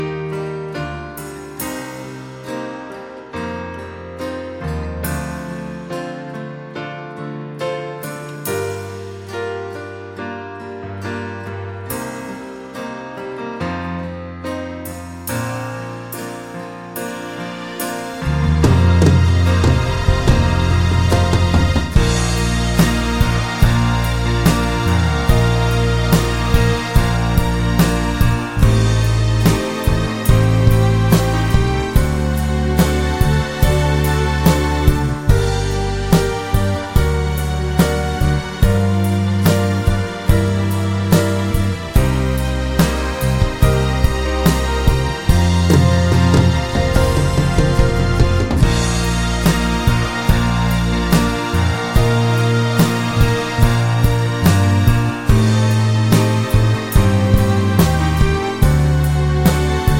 no Backing Vocals Country (Male) 4:16 Buy £1.50